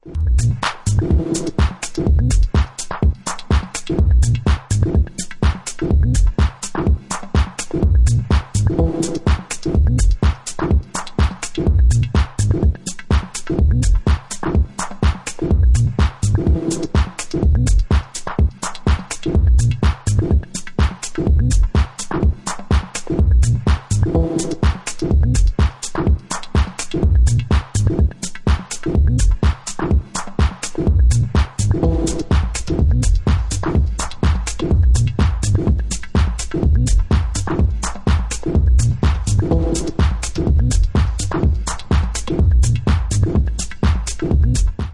Heavy driven raw techno !!
TECHNO/ELECTRO